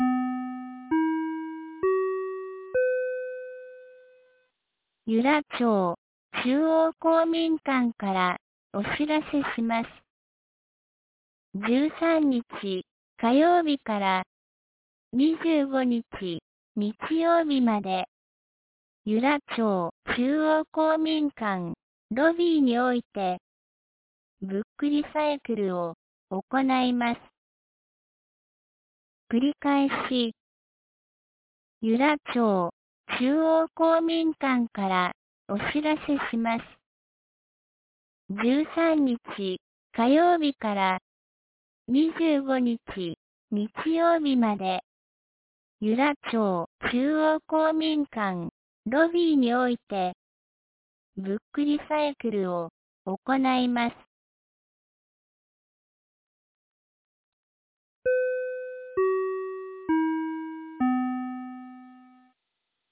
2020年10月10日 17時11分に、由良町から全地区へ放送がありました。